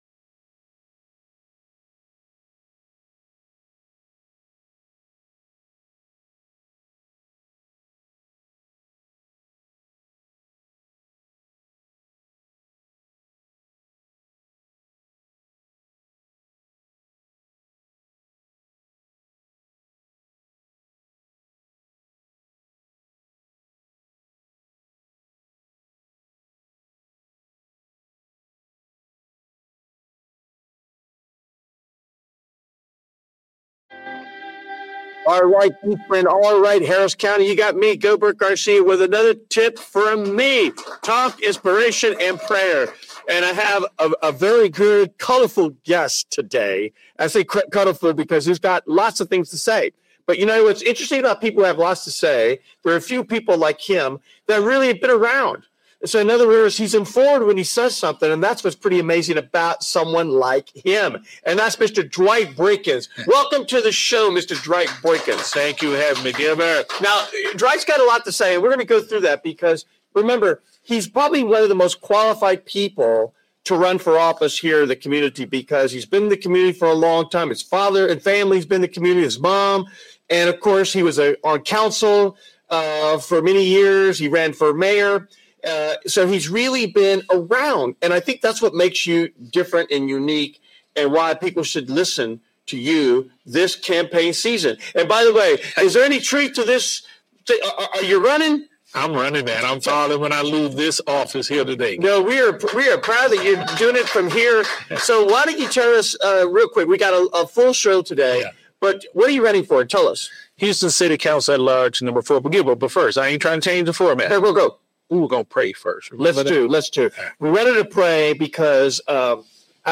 Talk, Inspiration & Prayer
LIVE Every Monday from 11:00am to 12:00pm CT on Facebook Live